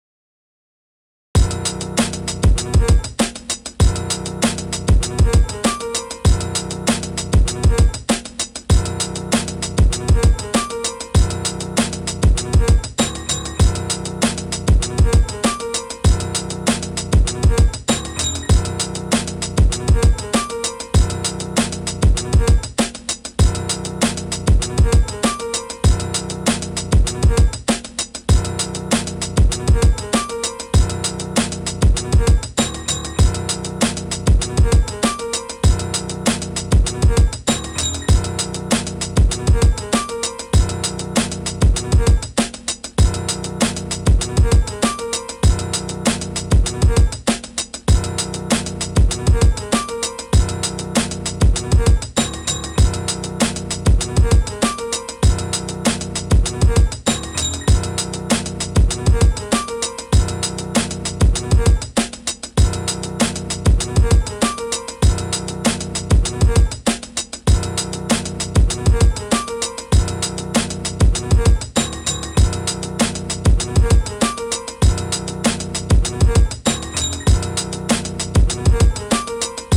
Here’s a quick boombap hiphop gritty beat I made in Beatmaker3 using BYOD / GuitarML and Pasttofuturereverbs Akai MPC60 12bit sampler AI profile & Studer J37 AI profile. No plugins used Just these .json profiles & Pasttofuturereverbs API EQ .json profile. All profiles on just one BUS / Aux